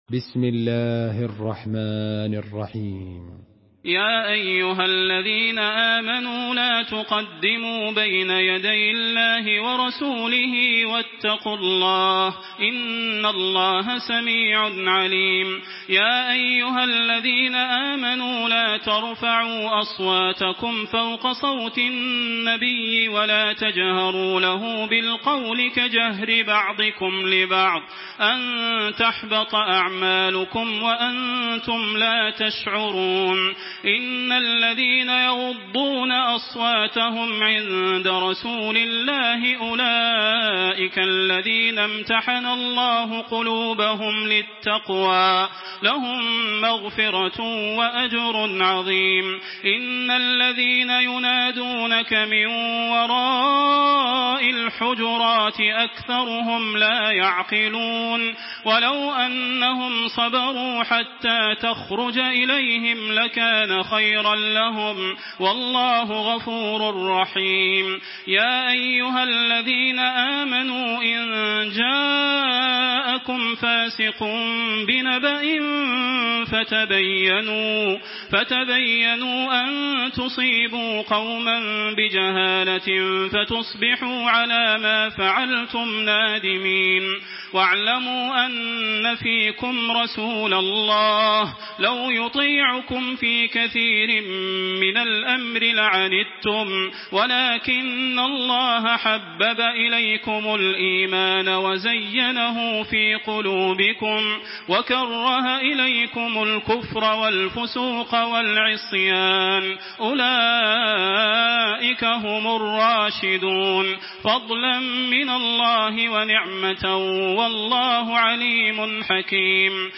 Murattal